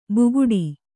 ♪ buguḍi